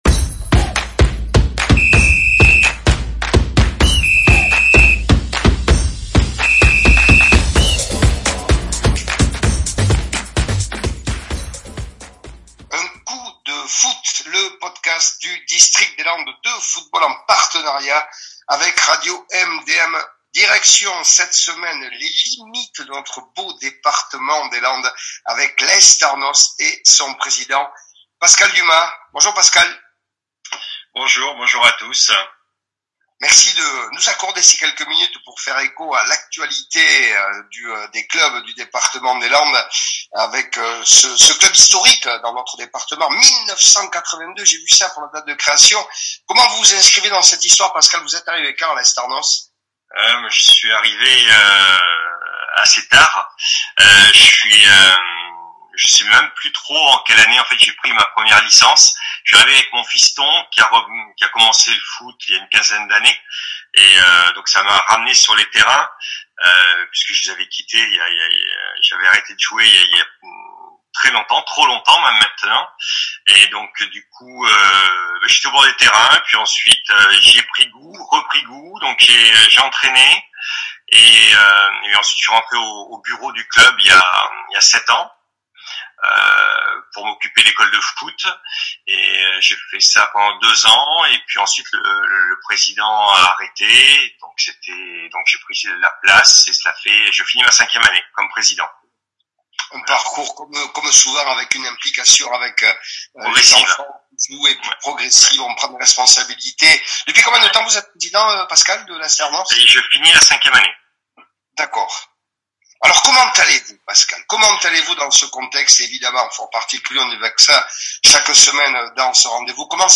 Retrouvez l’interview complète sur le podcast « Un coup de Foot ».